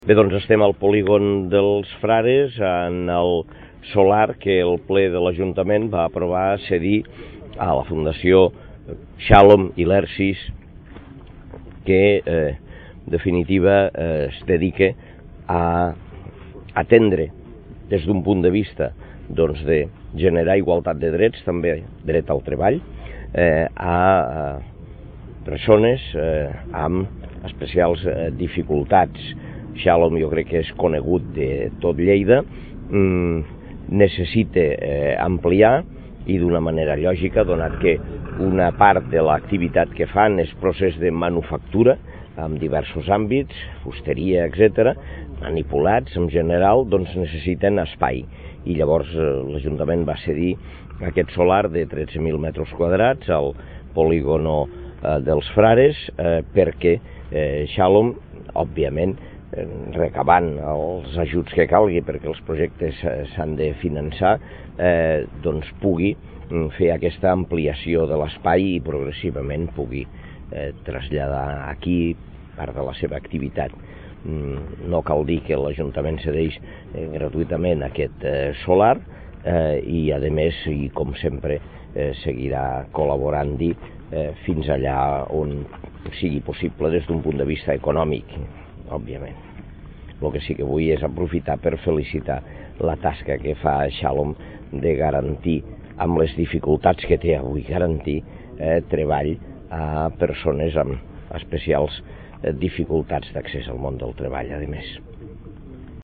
tall-de-veu-de-lalcalde-de-lleida-angel-ros-sobre-la-cessio-gratuita-dun-solar-municipal-a-la-fundacio-privada-ilersis